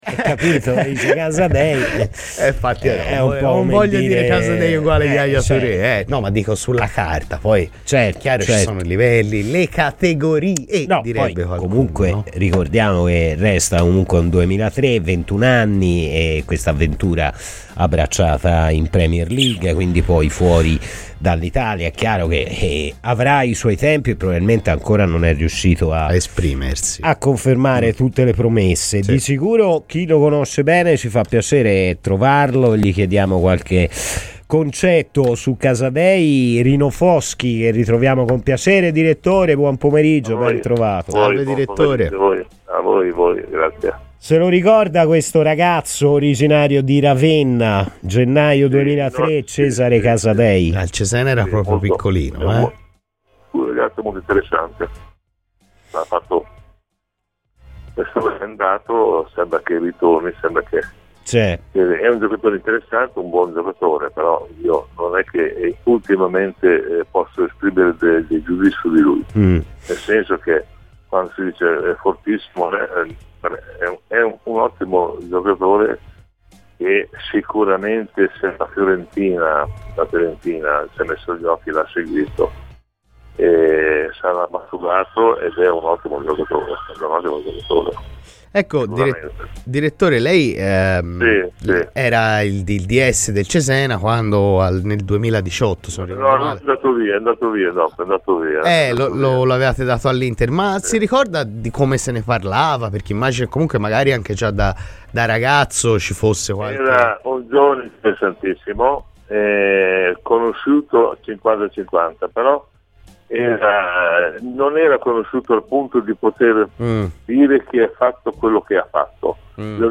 Stimavo molto Italiano che è stato un mio ex giocatore e che però è particolare, più presuntuoso forse, mentre Palladino è all'opposto; mi piace e il matrimonio promette bene" ASCOLTA IL PODCAST PER L'INTERVISTA COMPLETA